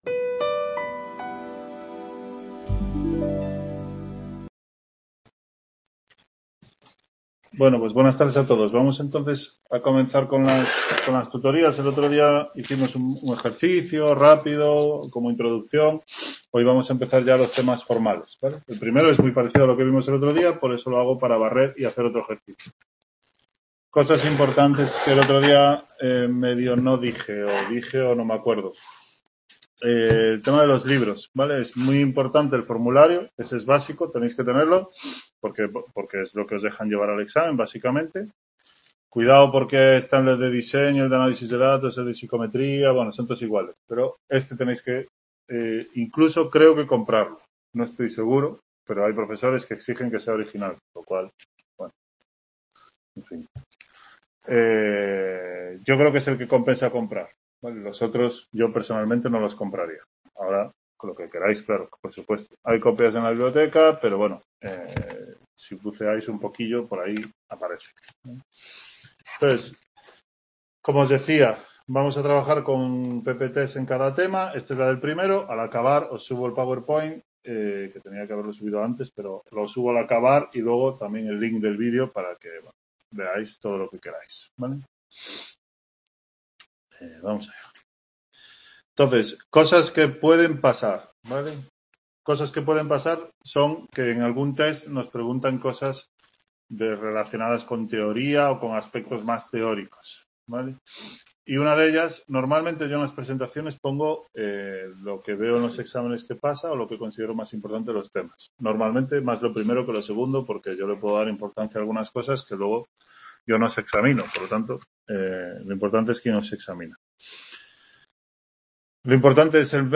Tutoría 1